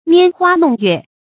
拈花弄月 注音： ㄋㄧㄢ ㄏㄨㄚ ㄋㄨㄙˋ ㄩㄝˋ 讀音讀法： 意思解釋： 玩賞花月。